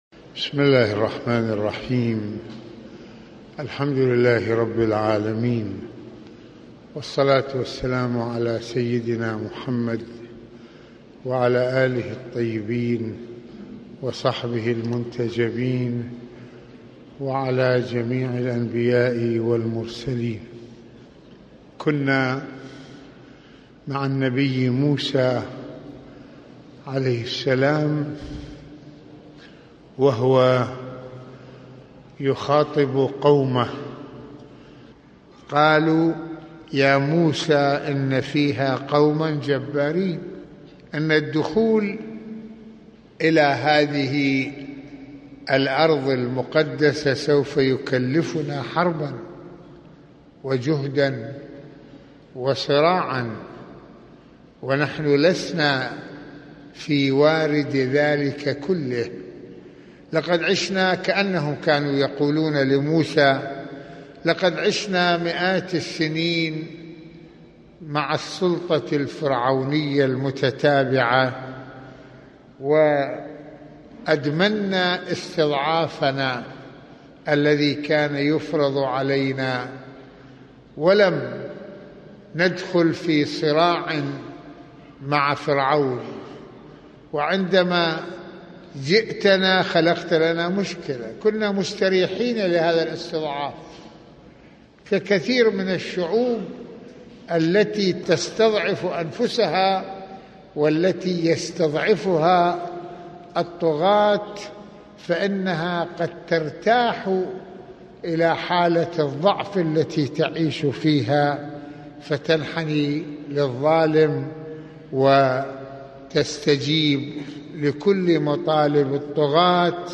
- يواصل المرجع السيد محمد حسين فضل الله (رض) في هذه المحاضرة حديثه عن قصة موسى مع قومه لجهة دخول بيت المقدس ويشير سماحته إلى حالة الاسترخاء التي أدمنها بنو اسرائيل في ظل سلطة الفراعنة وخوفهم من الجبارين في الأرض المقدسة كما ويتابع سماحته أهمية أن يأخذ أي شعب بأسباب العزة والقوة وعدم الخضوع للظلم والباطل عبر الثقة بالله والتوكل عليه...